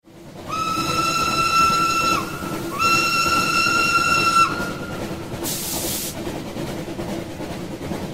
train.mp3